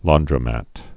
(lôndrə-măt, län-)